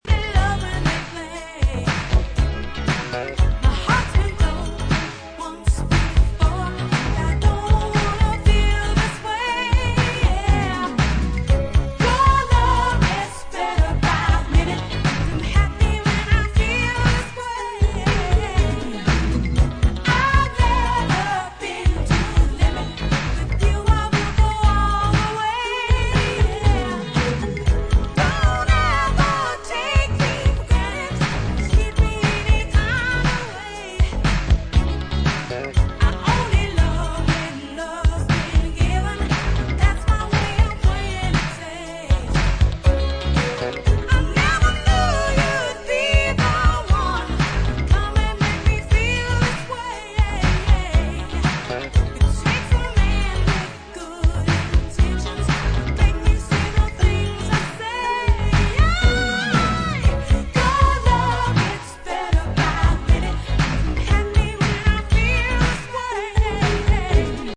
Recorded in the early 80's and remastered from cassete.
Amazing disco funk!
Disco Funk